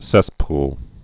(sĕspl)